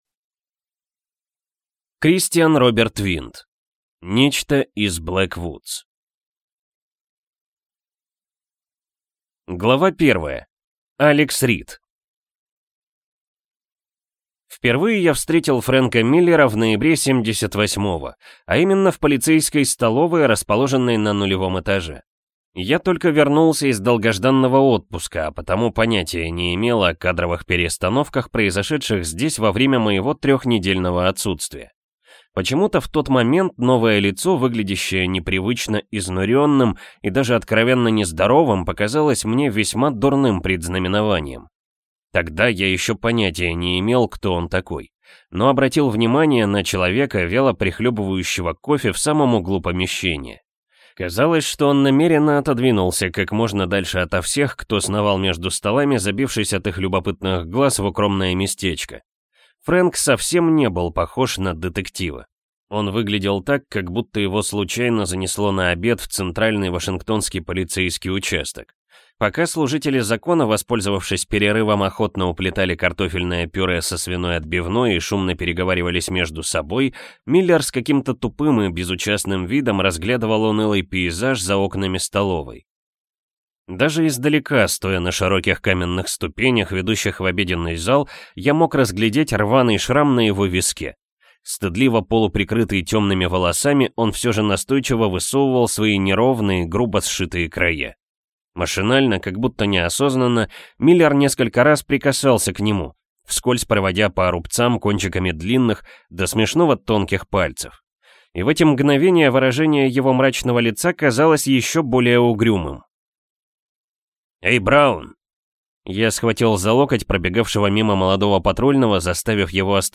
Аудиокнига Нечто из Блэк Вудс | Библиотека аудиокниг